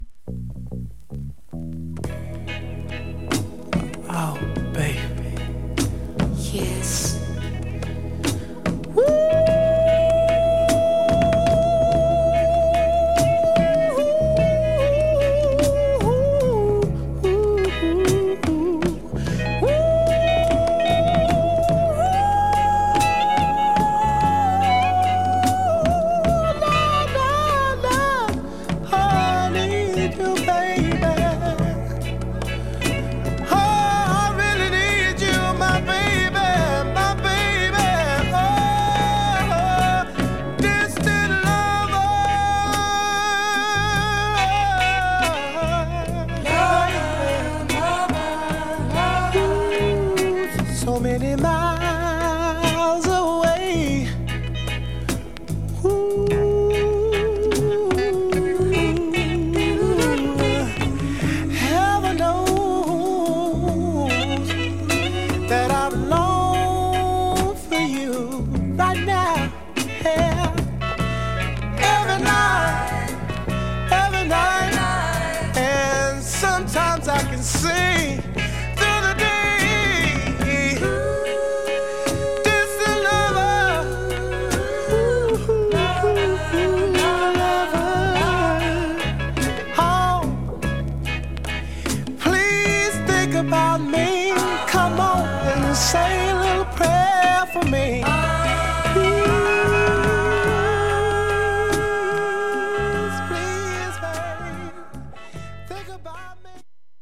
◇小キズが少々あり曲によってプツ音目立つ箇所あります